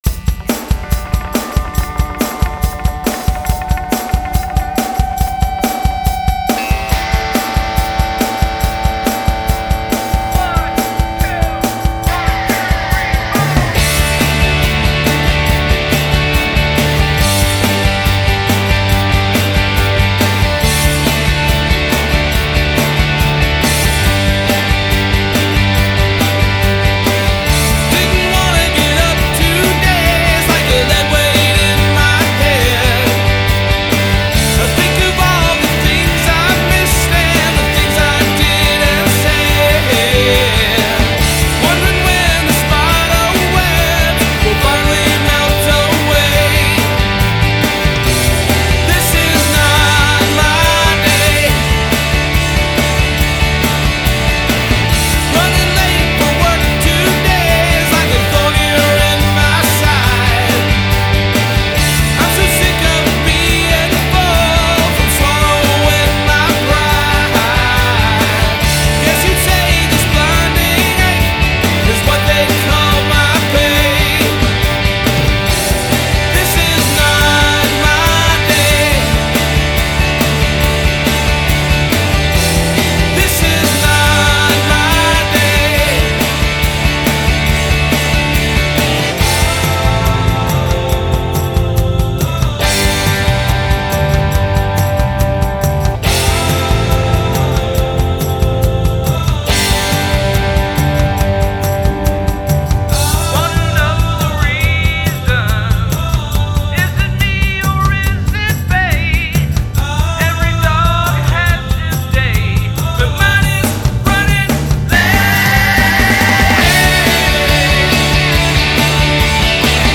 • Genre: Pop